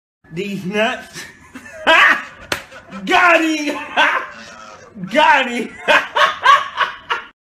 Deez Nuts Meme Sound Effect sound effects free download